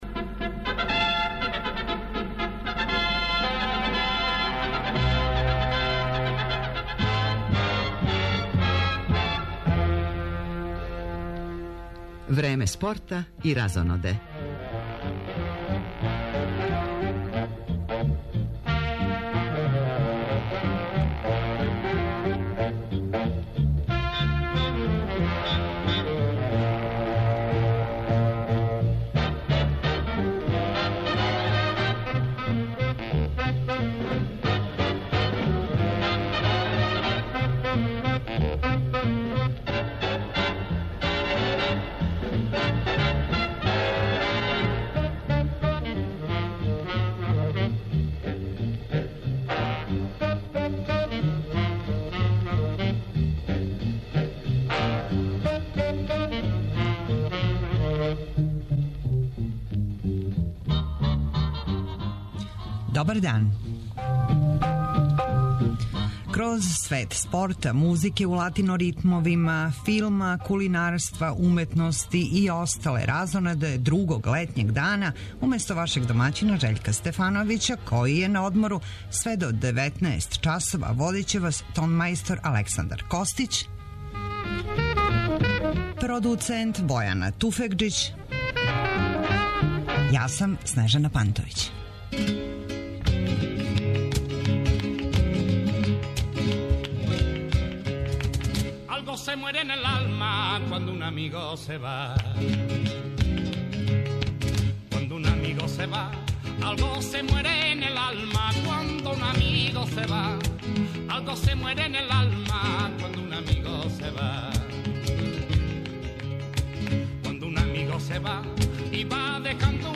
Лагани колаж биране латино музике, успешних гостију, спорта, културе и разоноде испуниће врело летње суботње поподне овог 22. јуна.